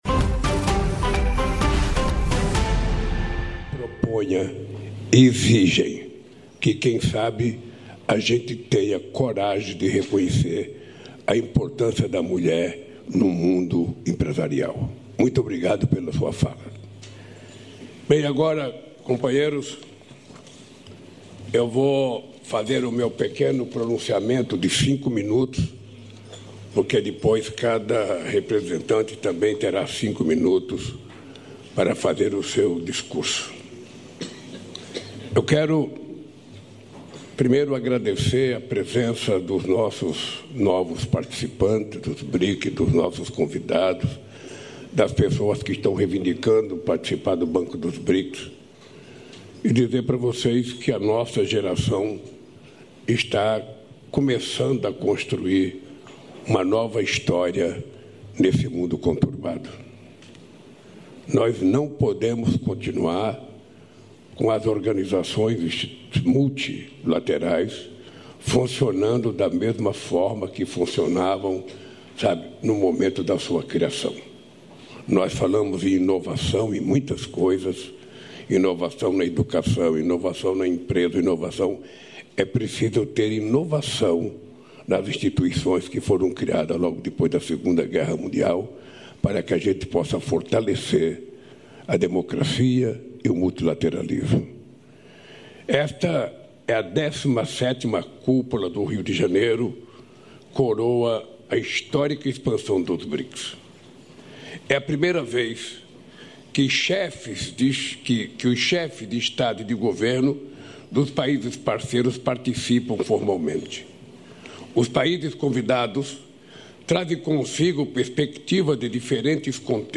Cúpula BRICS: Lula discursa sobre multilateralismo, economia e IA